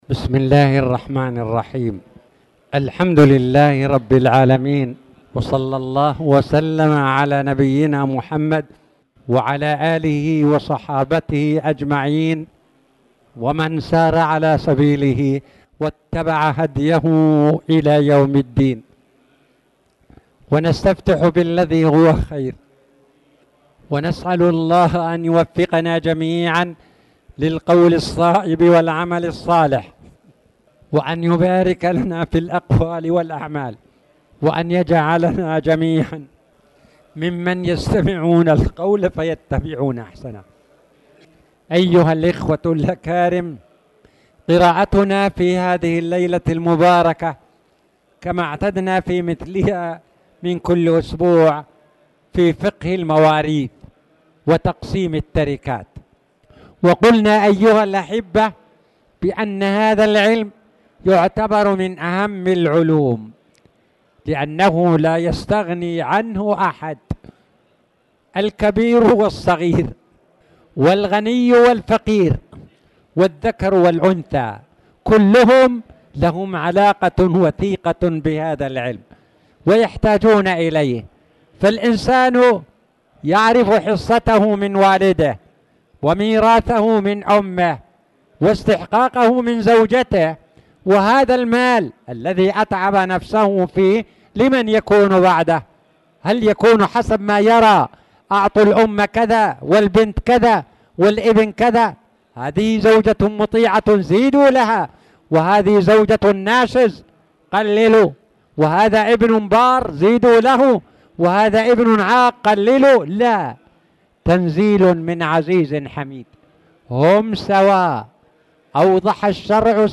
تاريخ النشر ٧ جمادى الآخرة ١٤٣٨ هـ المكان: المسجد الحرام الشيخ